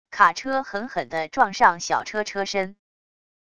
卡车狠狠地撞上小车车身wav音频